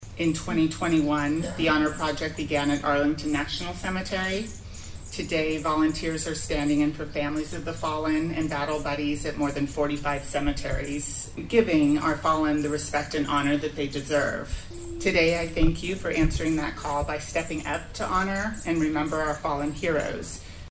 Service members and their families flocked on Memorial Day to the Kansas veteran’s Cemetery at Fort Riley where soldiers past and present were honored.